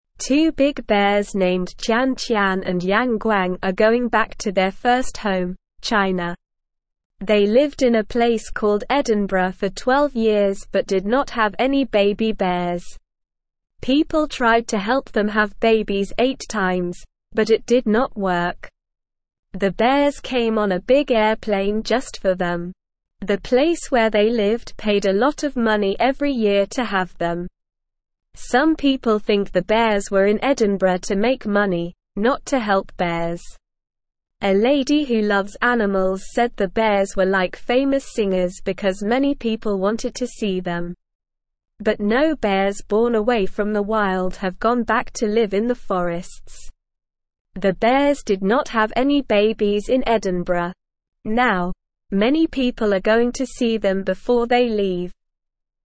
Slow
English-Newsroom-Beginner-SLOW-Reading-Big-Bears-Tian-Tian-and-Yang-Guang-Go-Home.mp3